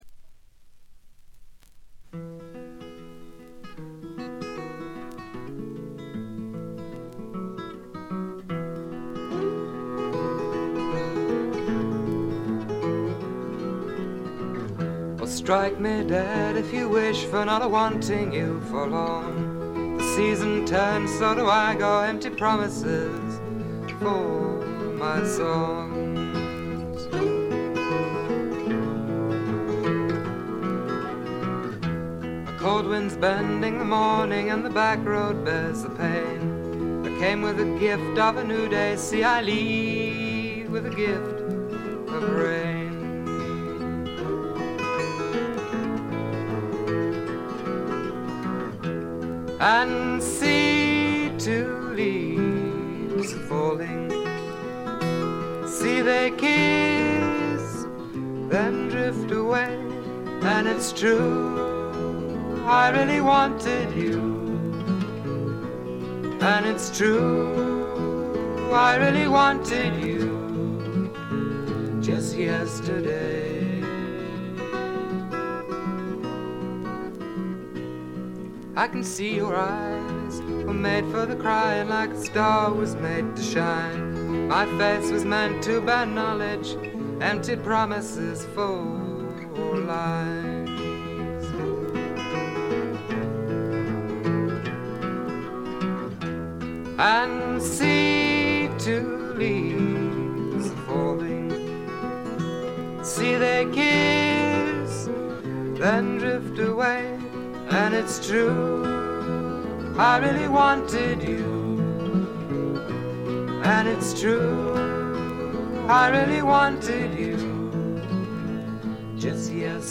軽微なチリプチ少し。
全編良好に鑑賞できます。
米国のドリーミー・フォーク的な感覚もありますね。
試聴曲は現品からの取り込み音源です。